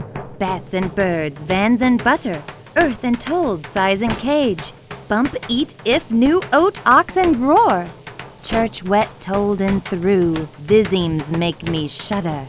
Recovered signal (M=4, Mu=0.1)
• There wasn't any perceptible difference between recovered signals with different Mu.